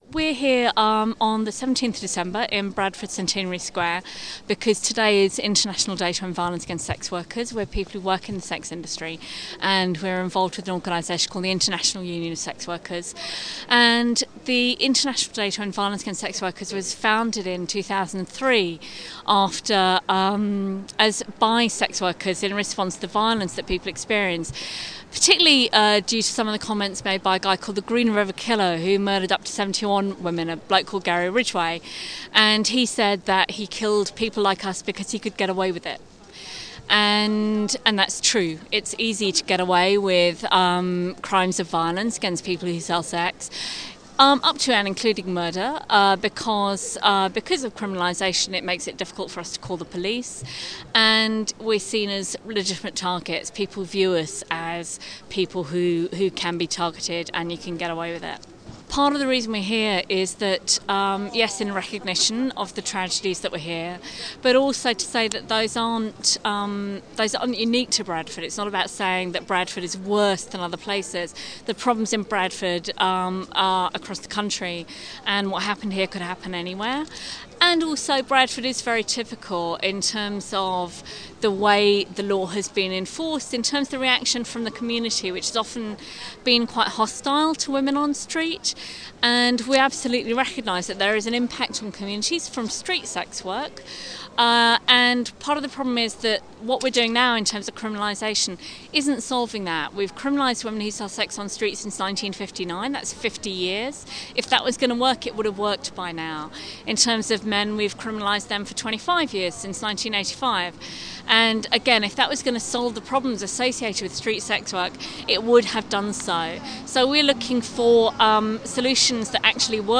Interview with the campaigners